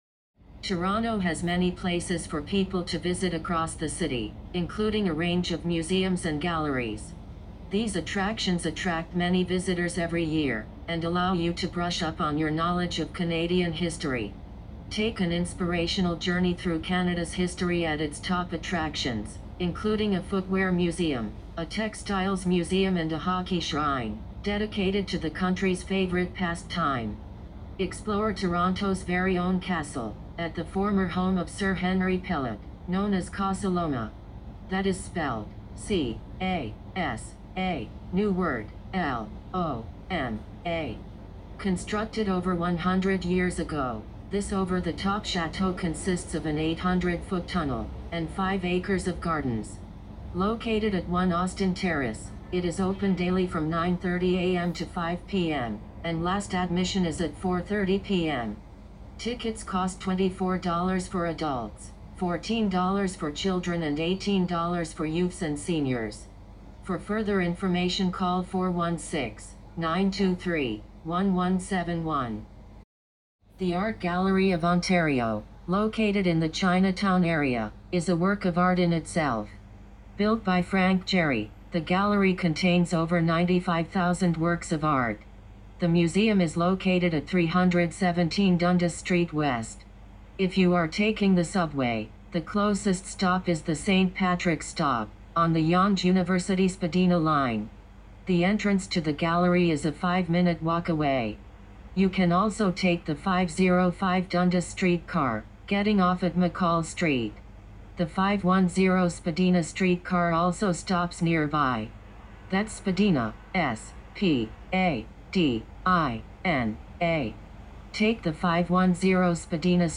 Listening Practice 7: Table Completion (North American Accent )